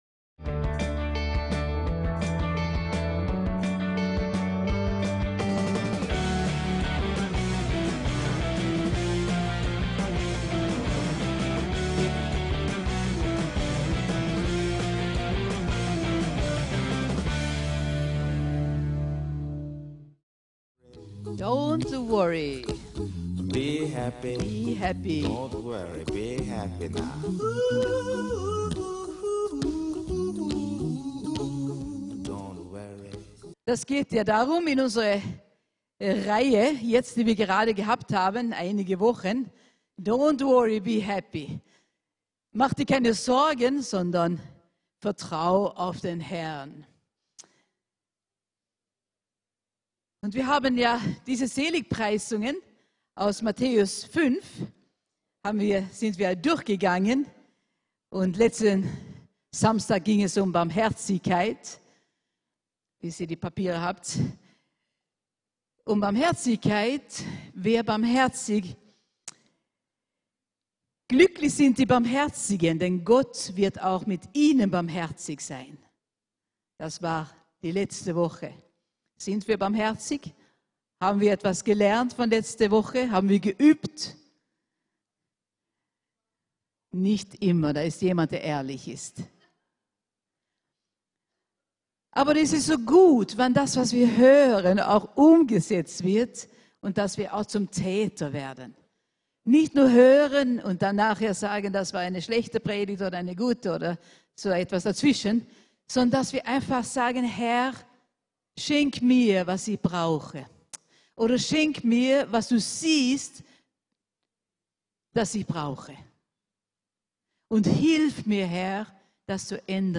VCC JesusZentrum Gottesdienste (audio) Podcast